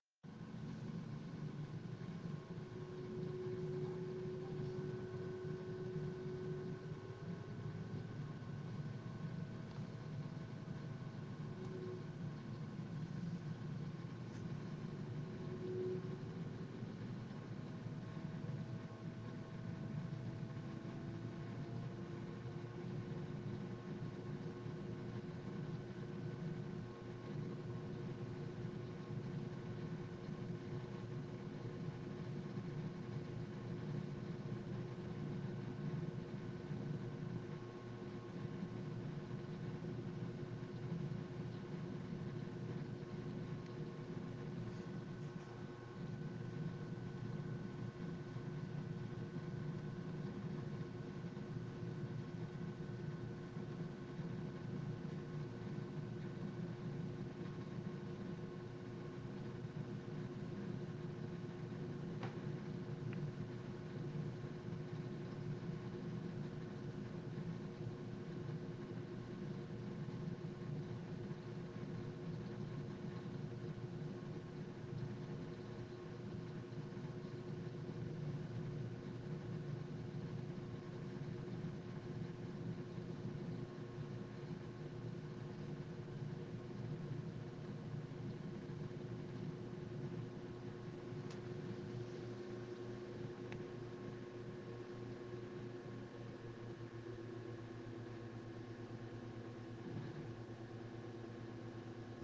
Zudem ist mir aufgefallen, dass der PC (dies allerdings schon länger) beim Starten für ca. anderthalb Minuten ein dumpfes, schleifendes (?) Geräusch von sich gibt, was danach verschwindet oder höchstens für eine halbe Sekunde ab und zu ertönt, hänge eine Audioaufnahme in den Anhang. Ich bedanke mich trotz meiner Unbeholfenheit für jegliche Antworten Anhänge PC-Startgeräusch.ogg 196,4 KB